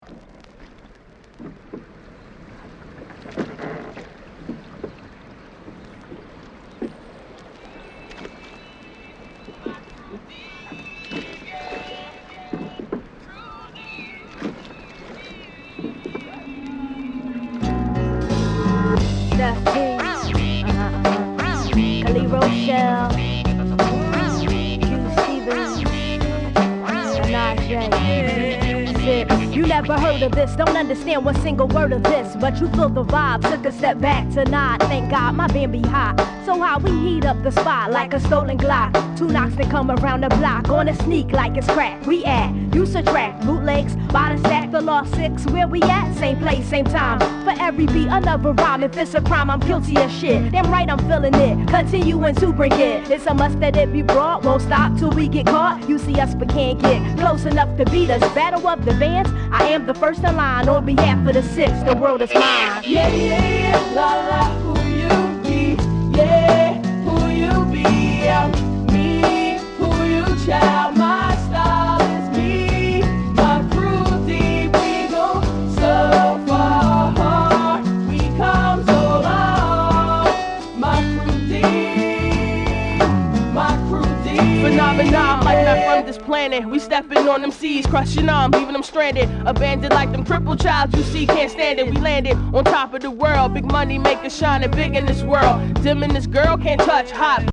当前位置 > 首页 >音乐 >唱片 >R＆B，灵魂